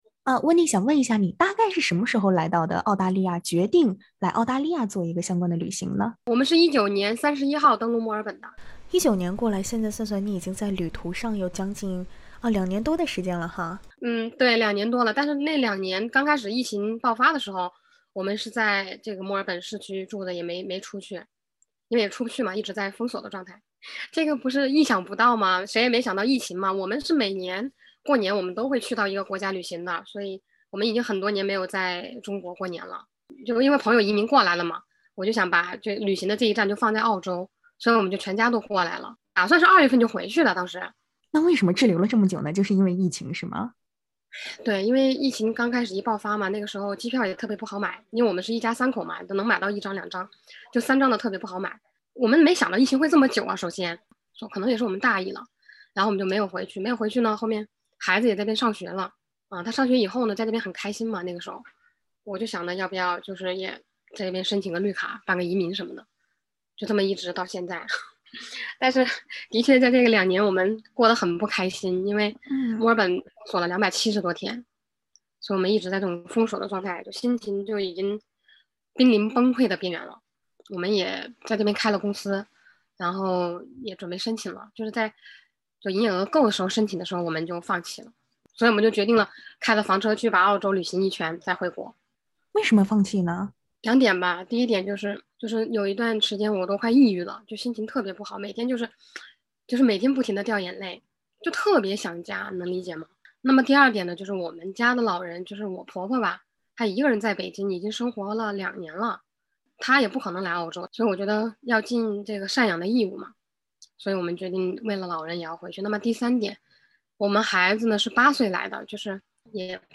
请听采访： LISTEN TO 一场被迫延长两年的新年旅行：华人家庭的环澳故事 SBS Chinese 08:19 cmn 本文系SBS中文普通话节目原创内容，未经许可，不得转载。